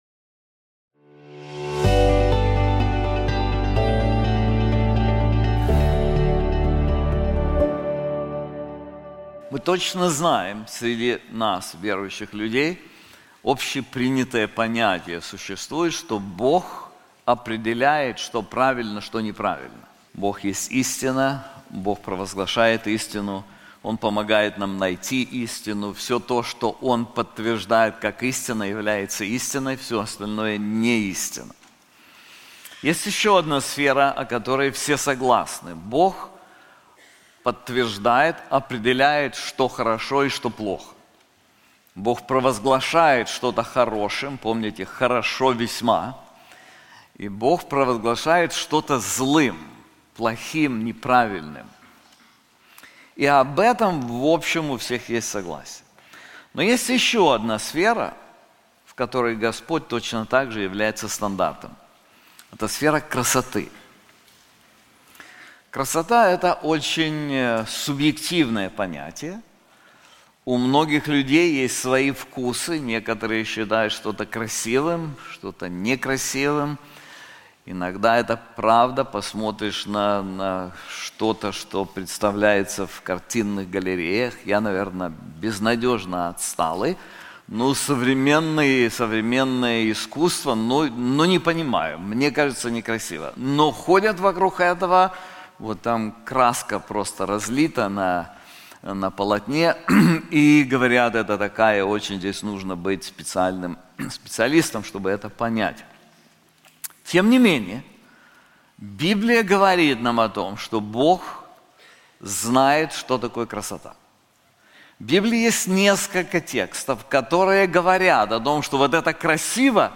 This sermon is also available in English:The Beauty of the Good News • Isaiah 52:1-12